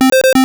retro_synth_beeps_groove_07.wav